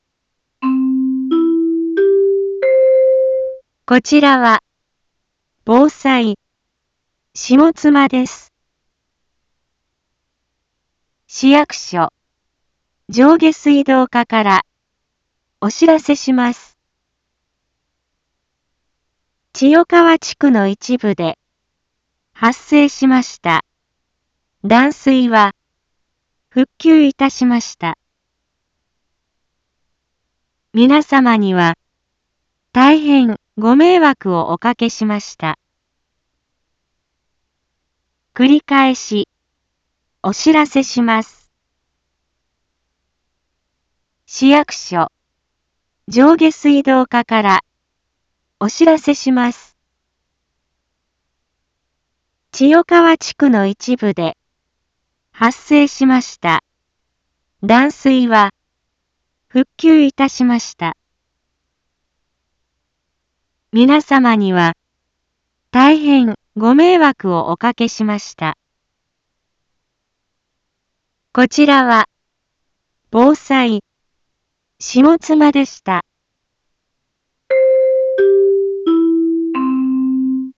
Back Home 一般放送情報 音声放送 再生 一般放送情報 登録日時：2022-12-27 10:31:25 タイトル：千代川地区断水の解消について インフォメーション：こちらは、防災、下妻です。